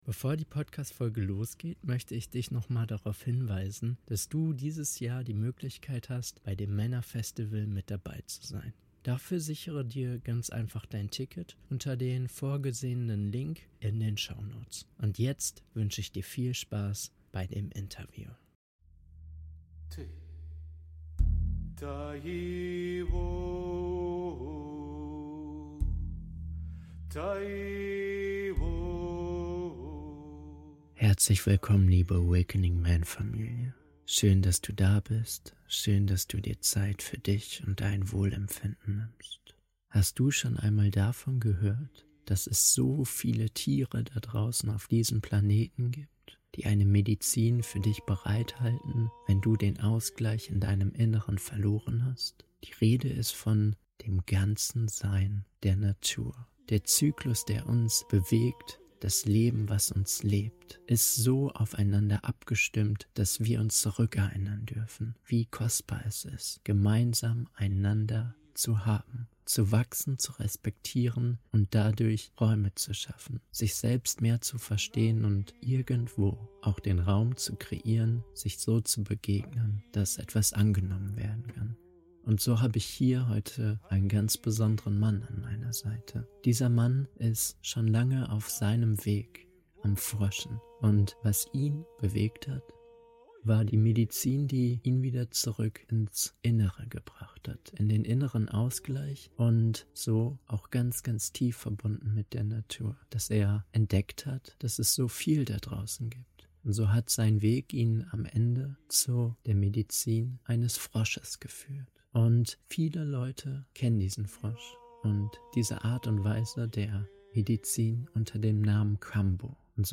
Kambo als Tor zur inneren Wahrheit - Interview